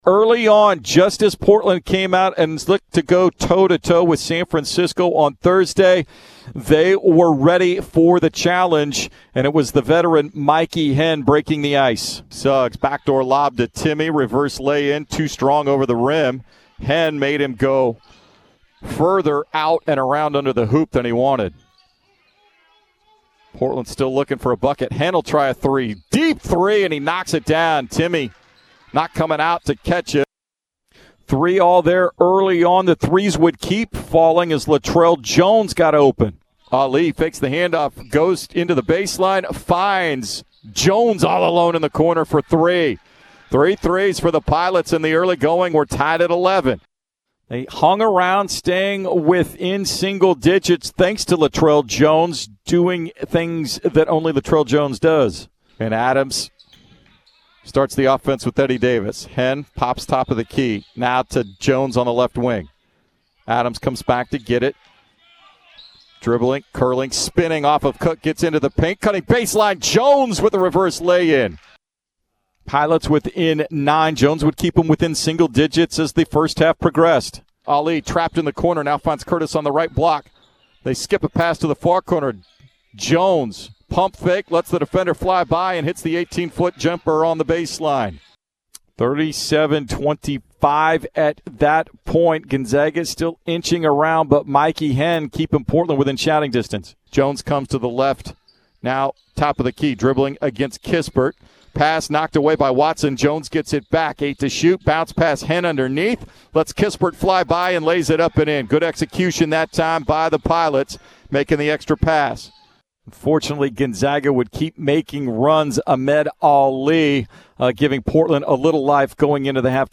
Men's Basketball Radio Highlights vs. Gonzaga
January 09, 2021 Radio highlights from Portland's 116-88 loss to the No. 1-ranked Gonzaga Bulldogs on Jan. 9, 2021 at teh Chiles Center.